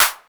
Snare_01.wav